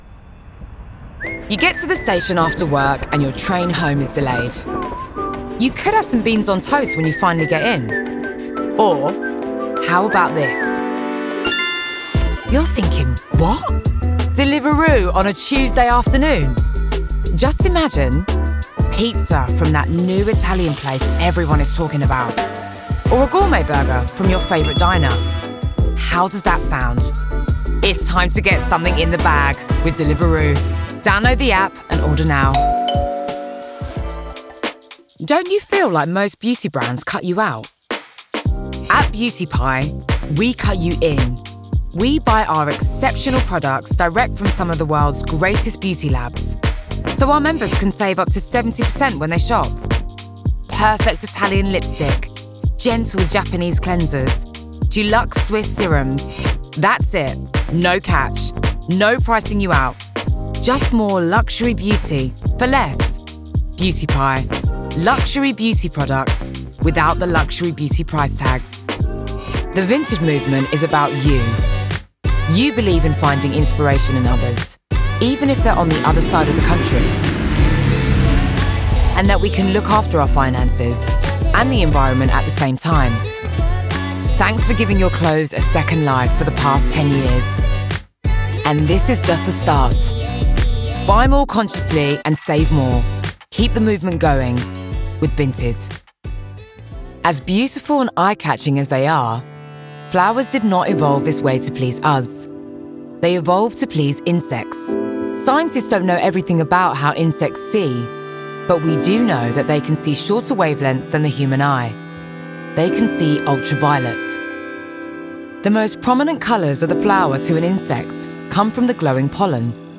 Neutral London.
• Female
• London
urban, cool